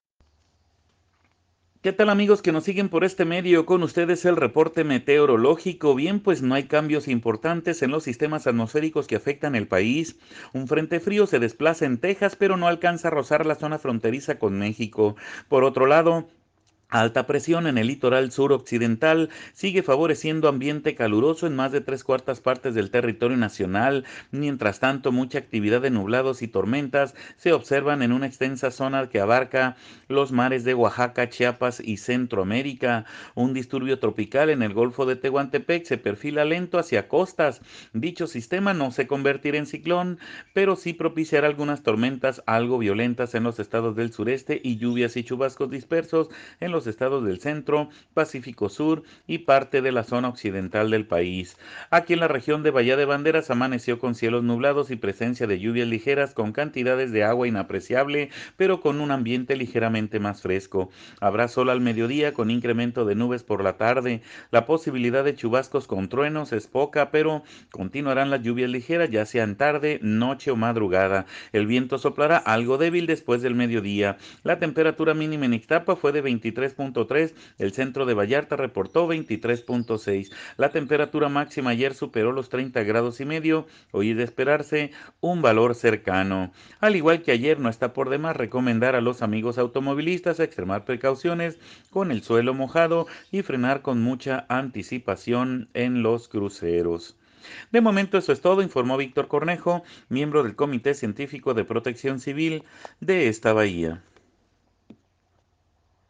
escuche al meteorólogo